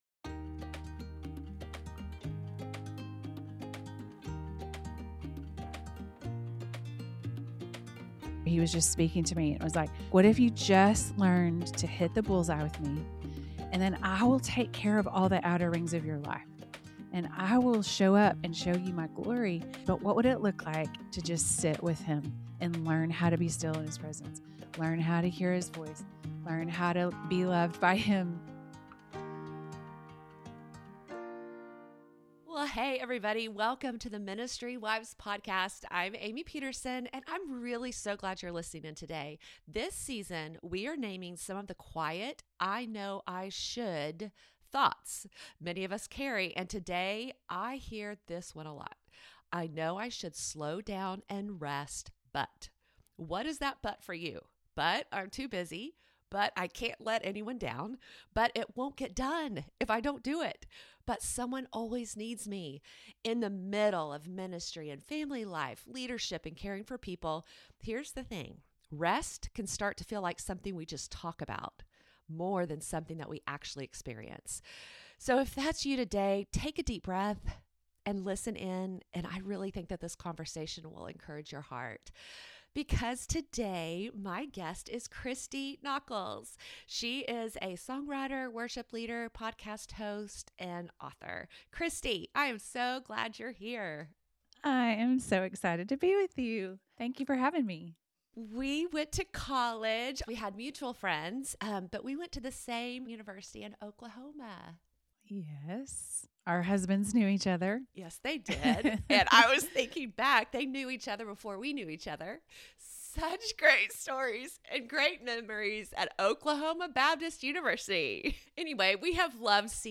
If you’ve ever felt the tension between ministry demands and soul-deep exhaustion, this conversation is for you. In this episode of the Ministry Wives podcast